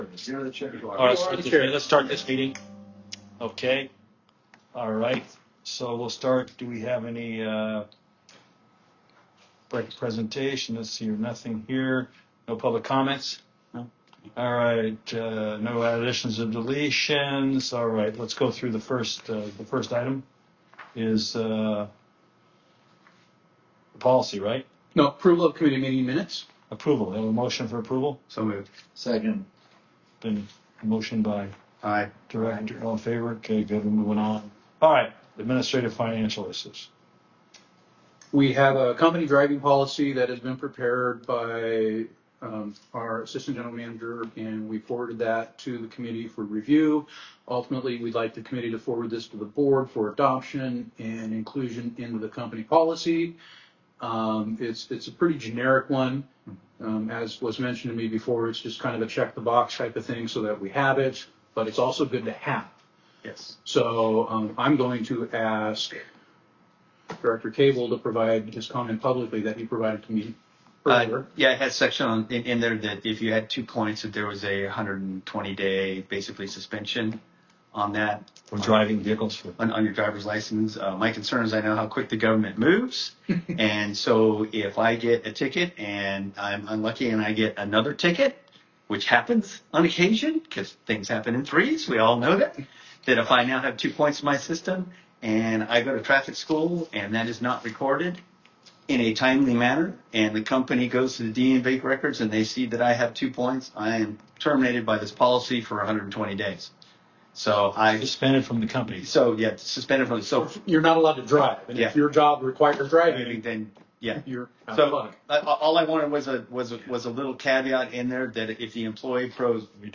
Administration And Finance Committee Meeting